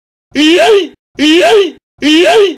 YEET! Sound effect